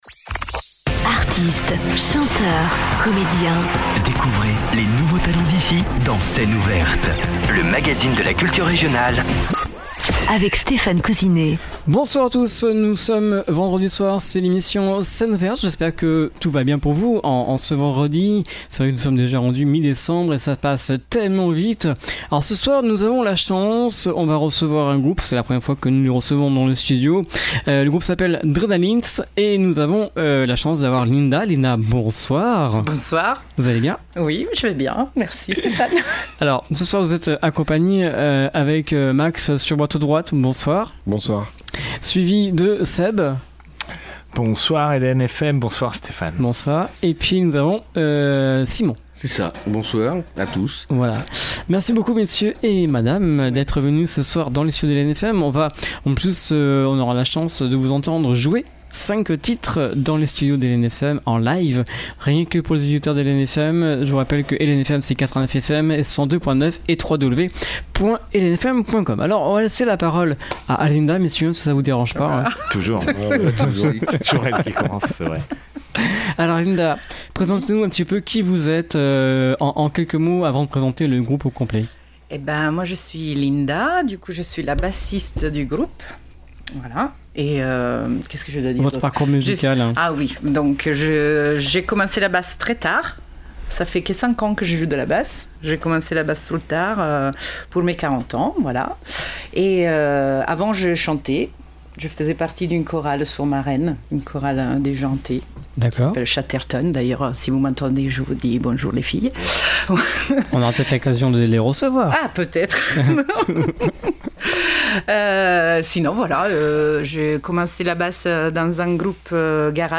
Une passion commune pour le rock